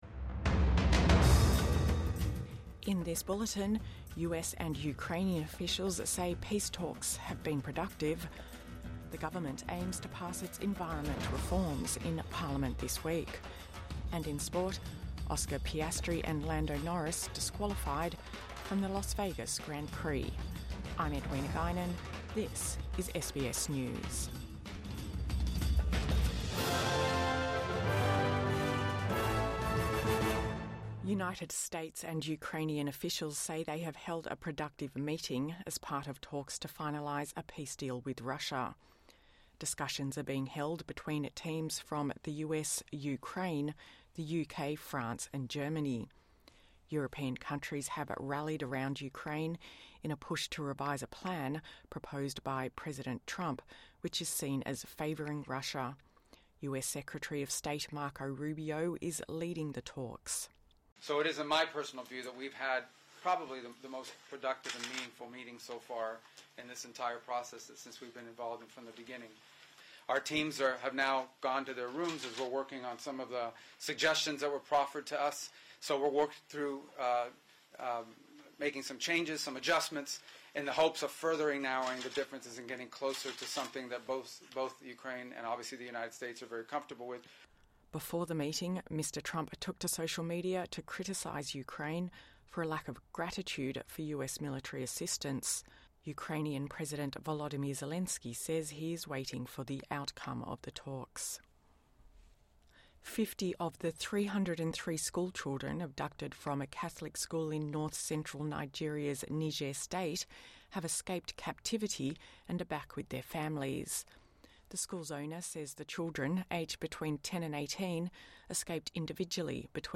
Ukraine peace plan talks 'productive'| Morning News Bulletin 24 November 2025